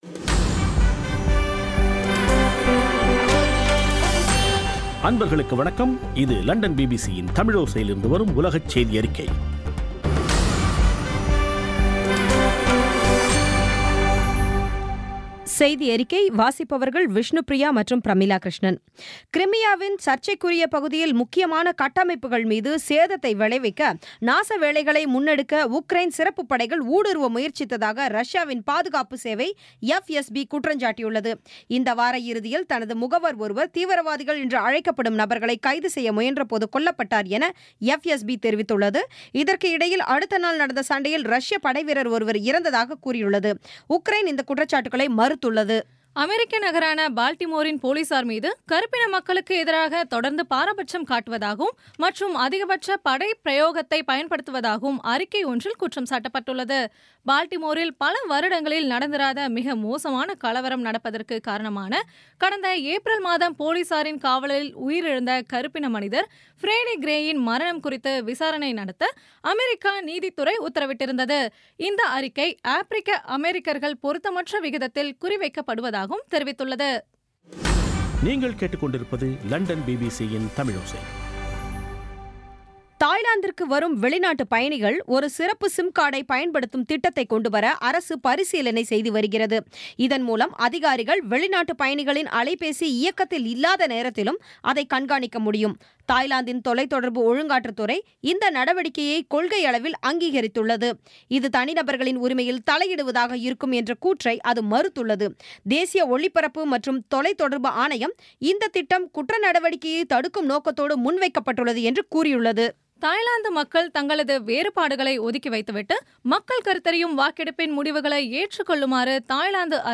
பி பி சி தமிழோசை செய்தியறிக்கை (10/08/2016)